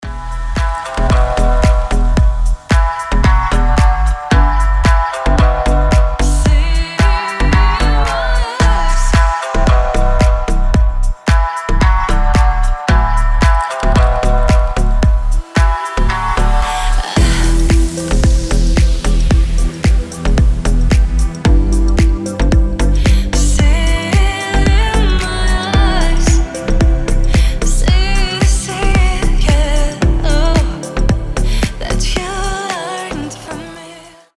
• Качество: 320, Stereo
женский вокал
deep house
восточные мотивы
красивая мелодия
релакс
чувственные